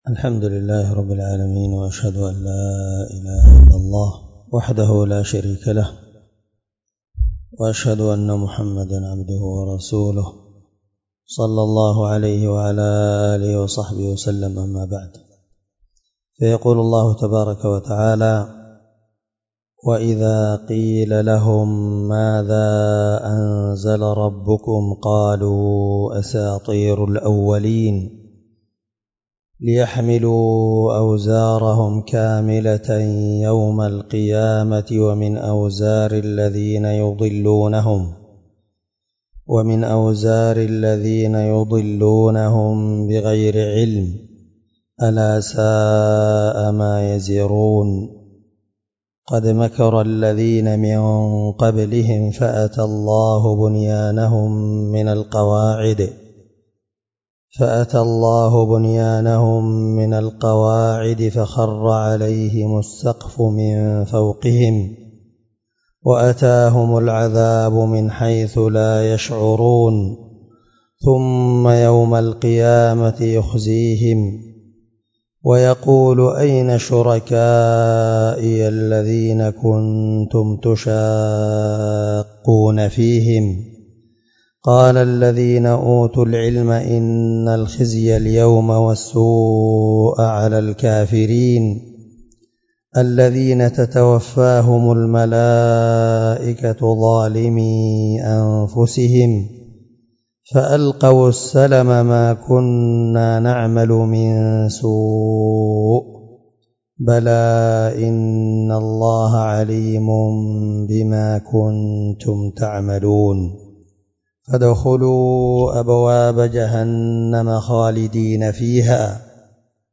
الدرس 6 تفسير آية (24-29) من سورة النحل